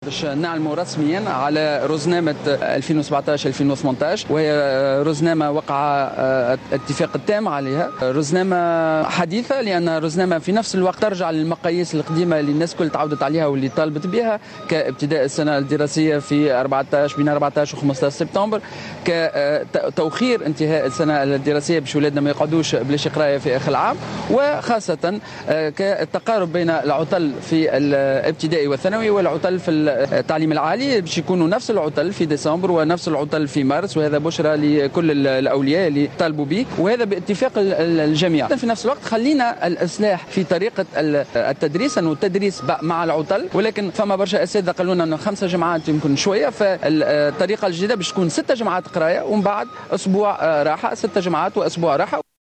أكد وزير التربية بالنيابة سليم خلبوص في تصريح اعلامي اليوم الأحد 4 ماي 2017 أنه سيعلن غدا رسميا عن رزنامة االسنة الدراسية 2018/2017.